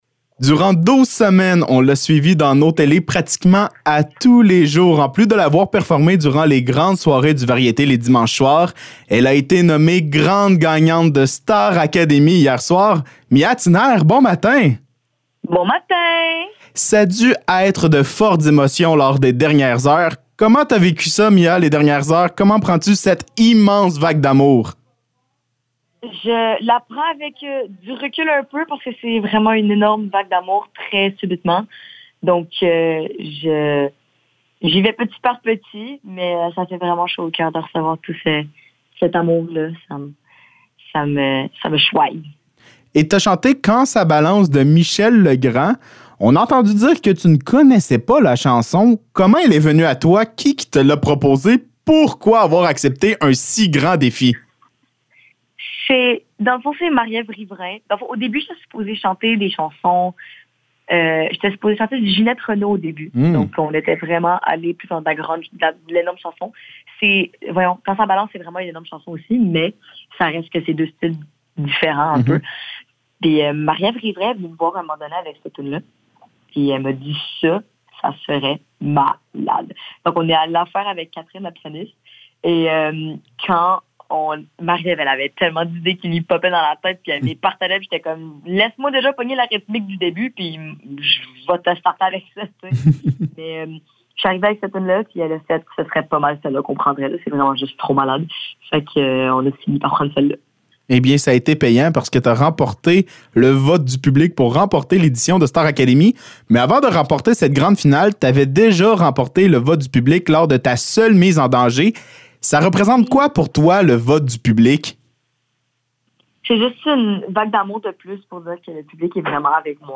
Entrevue avec Mia Tinayre
Entrevue avec Mia Tinayre, grande gagnante de Star Académie 2025, concernant son aventure et les derniers jours passés à l’Académie.
ENTREVUE-MIA-TINAYRE.mp3